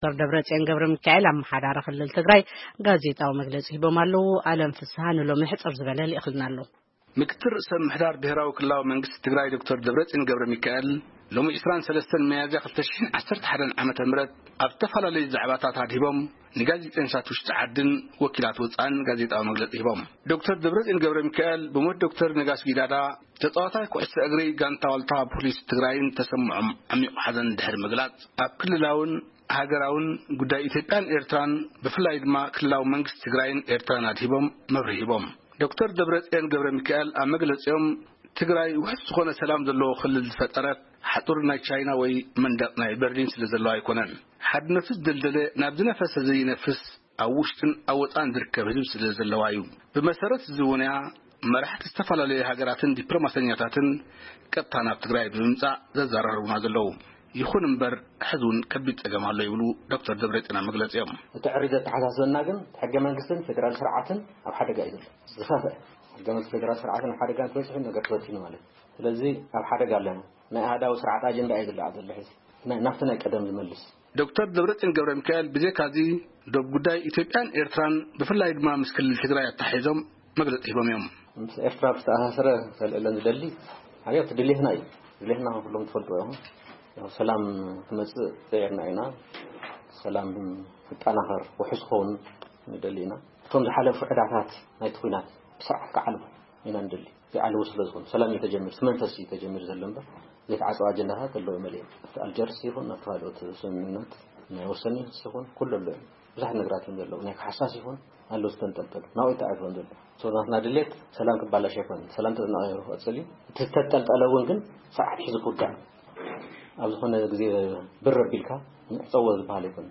ምክትል ርእሰ ምምሕዳር ብሄራዊ ክልላዊ መንግስቲ ትግራይ ዶ/ር ደብረጽየን ገብረሚካኤል ሎሚ ግንቦት 1,2019 ኣብ ዝተፈላለየ ዛዕባታት ኣድሂቦም ንጋዜጠኛታት ውሽጢ ሃገርን ወኪላት መሓውራት ዜና ወጻኢ ሃገራትን ጋዜጣዊ መግለጺ ሂቦም።